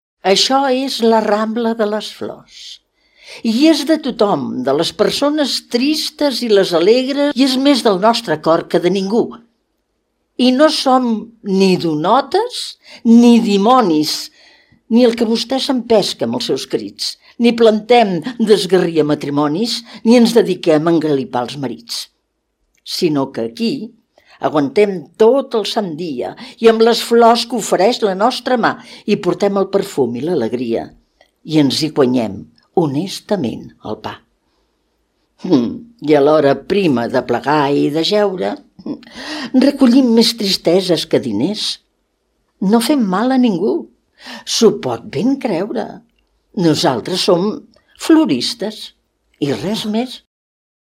Radioteatre: La Rambla de les Floristes - Matadepera Ràdio, 2015